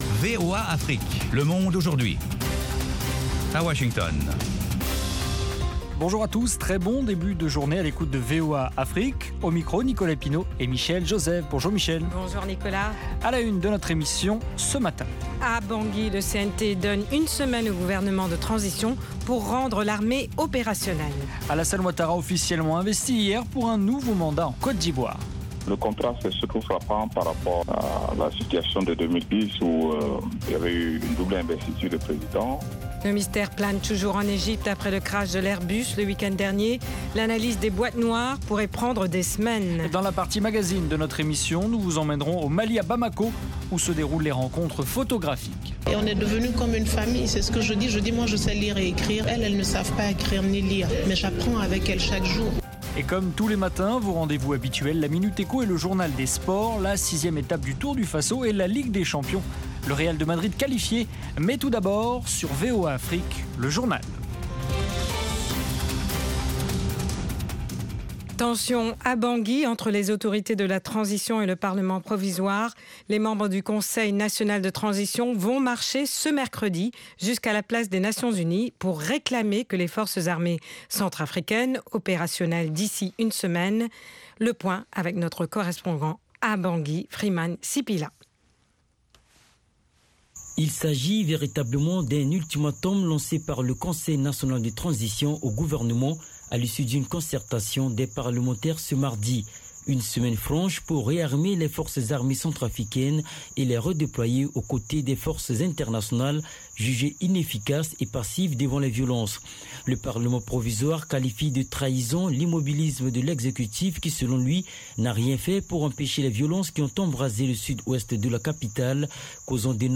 Le Monde aujourd'hui, édition pour l'Afrique de l'Ouest, une information de proximité pour mieux aborder les préoccupations de nos auditeurs en Afrique de l’Ouest. Toute l’actualité sous-régionale sous la forme de reportages et d’interviews.
Le Monde aujourd'hui, édition pour l'Afrique de l’Ouest, c'est aussi la parole aux auditeurs pour commenter à chaud les sujets qui leur tiennent à coeur.